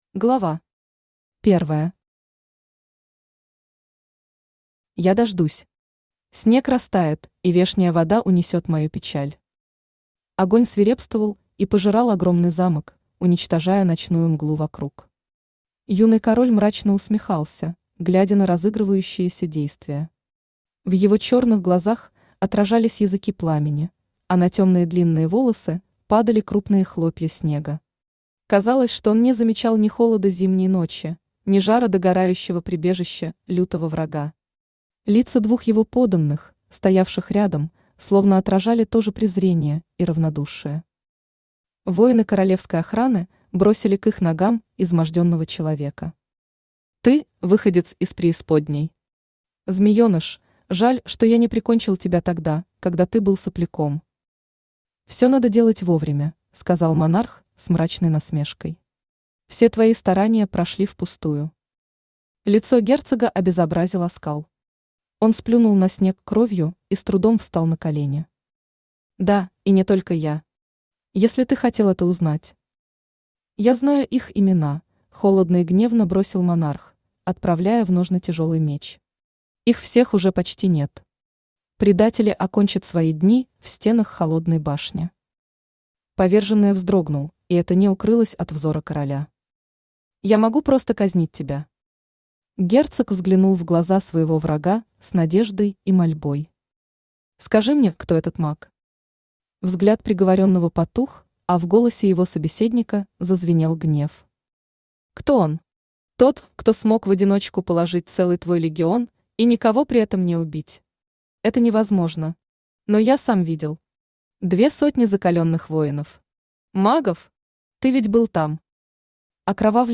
Aудиокнига Отражение ночи Автор Альбина Николаевна Севенкова Читает аудиокнигу Авточтец ЛитРес.